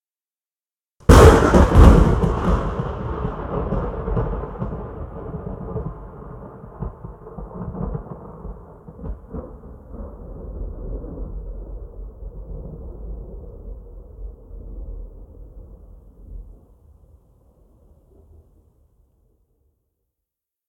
AS-SFX-Thunder 1.ogg